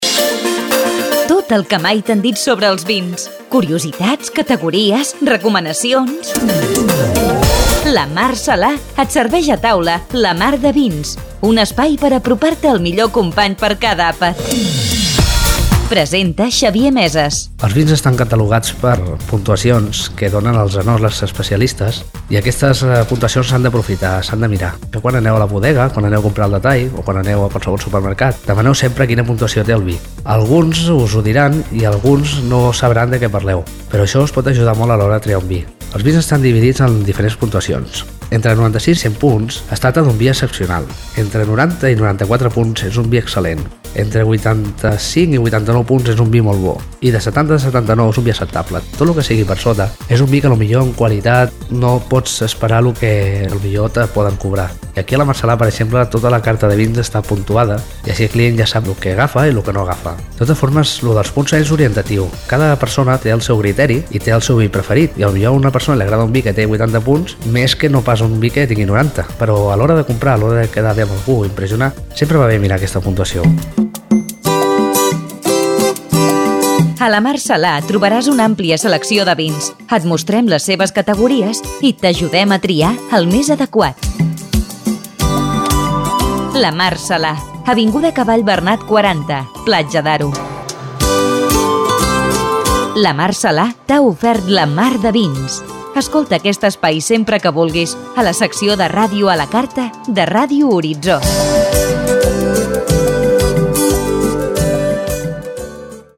Careta del programa i explicació de com es puntuen els vins
Divulgació